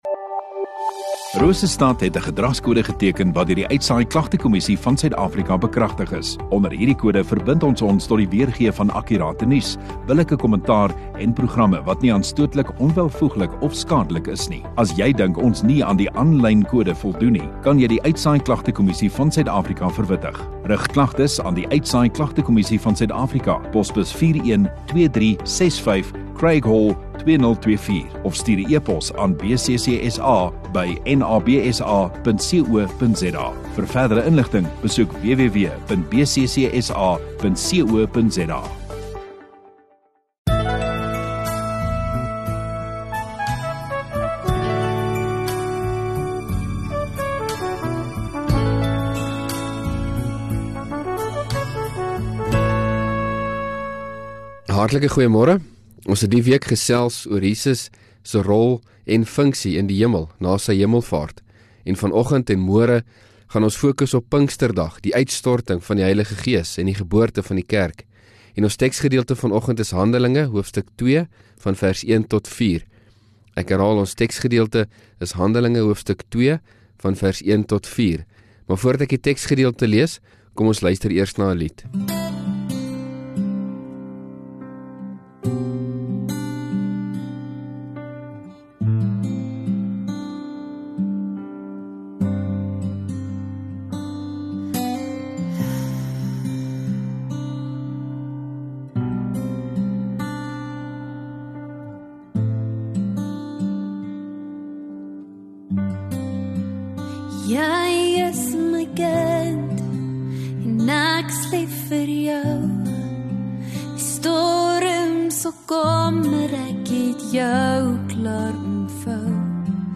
Oggenddiens